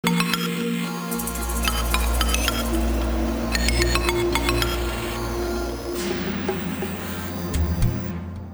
без слов
инструментальные
электронные